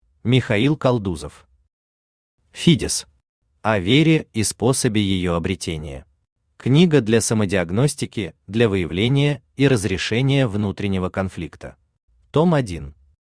Аудиокнига Фидес. О вере и способе её обретения | Библиотека аудиокниг
О вере и способе её обретения Автор Михаил Константинович Калдузов Читает аудиокнигу Авточтец ЛитРес.